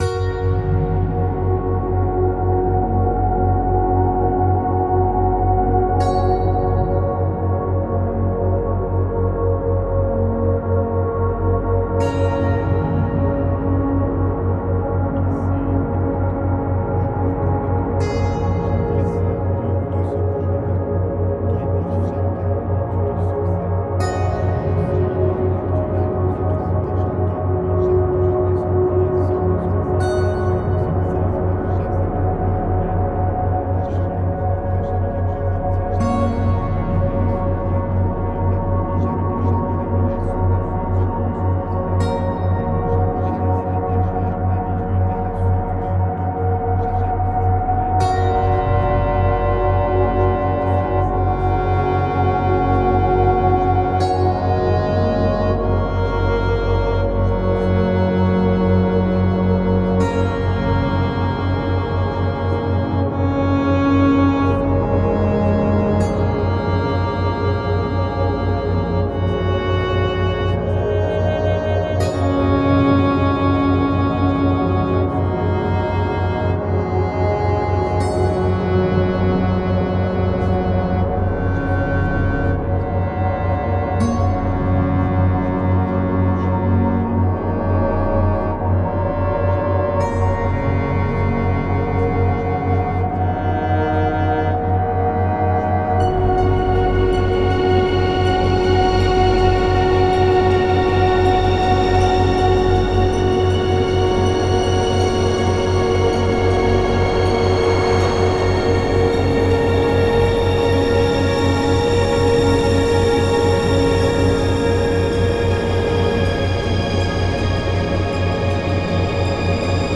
(Version RELAXANTE)
Alliage ingénieux de sons et fréquences curatives, très bénéfiques pour le cerveau.
Pures ondes thêta apaisantes 4Hz de qualité supérieure.
SAMPLE-Guru-social-2-relaxant.mp3